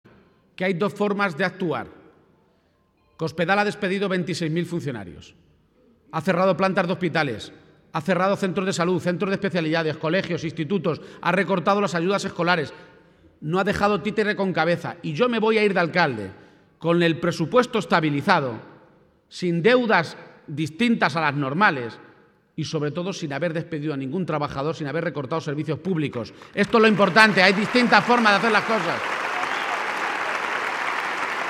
García-Page se pronunciaba de esta manera esta tarde, en Toledo, en un acto político que ha congregado a más de 1.500 personas en el Palacio de Congresos de la capital regional y en el que ha compartido escenario con el secretario general del PSOE, Pedro Sánchez, y la candidata socialista a suceder al propio García-Page en la alcaldía de Toledo, Mlagros Tolón.